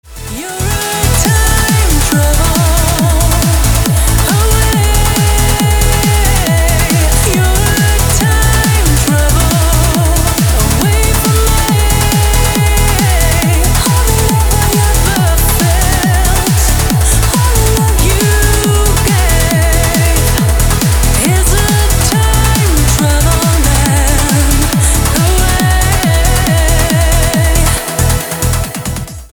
• Качество: 320, Stereo
громкие
dance
Electronic
EDM
электронная музыка
club
Trance
vocal trance